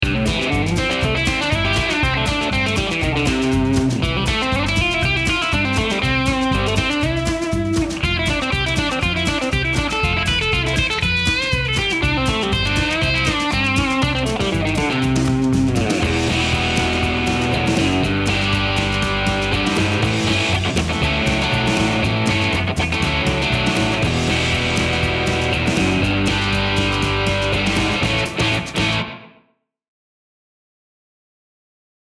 This Vintage Tele lead pickup uses alnico 2 rod magnets to provide a softer treble attack for players who want Telecaster tone without excess bite.
APTL-1_DIRTY_BAND_SM